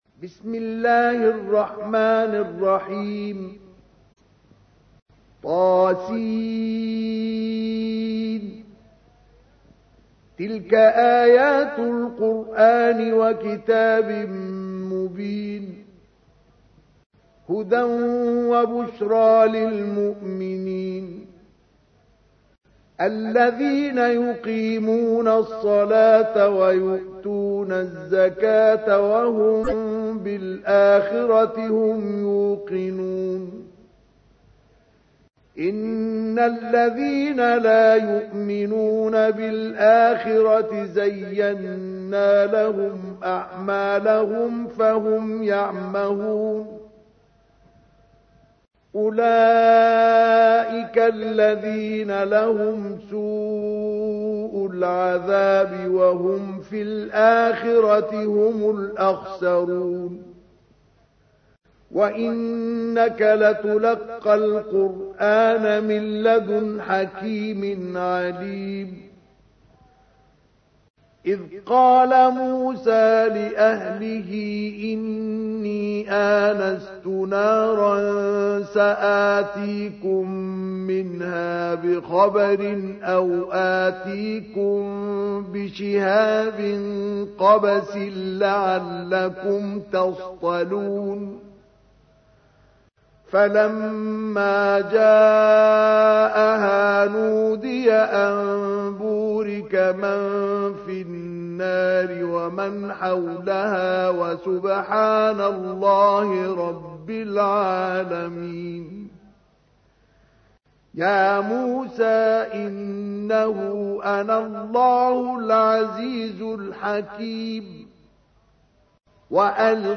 تحميل : 27. سورة النمل / القارئ مصطفى اسماعيل / القرآن الكريم / موقع يا حسين